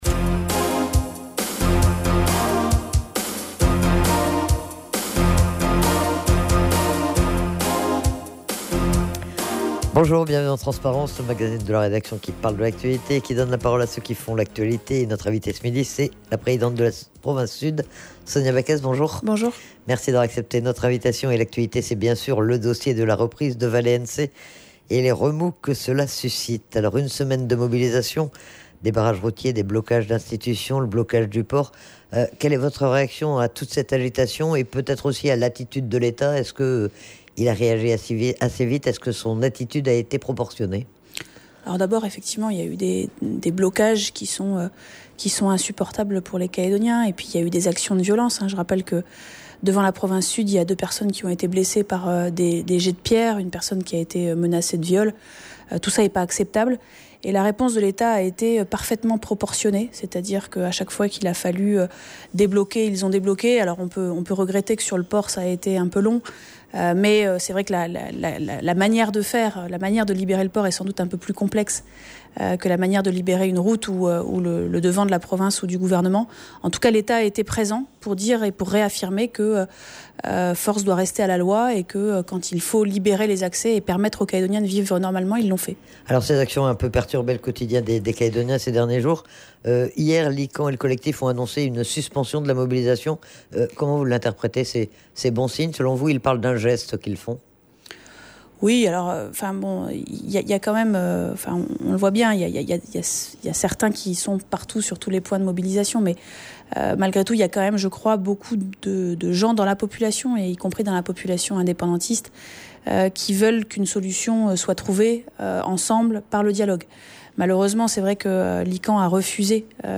Elle était interrogée sur la solution de reprise et sur le rôle de la Province mais aussi sur le contexte politique et sur les réactions qui entourent ce dossier industriel.